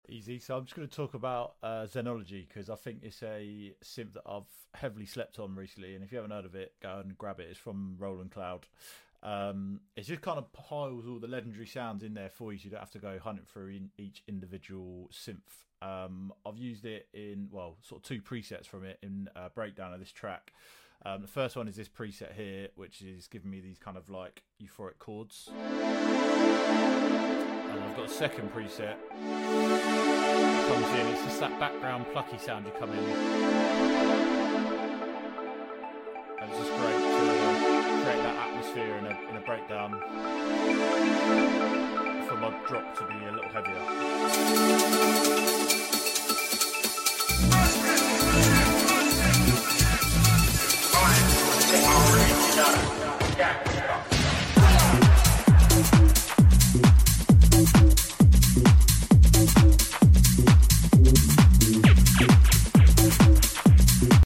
Exploring the Powerful Zenology Synth sound effects free download
Exploring the Powerful Zenology Synth from Roland Cloud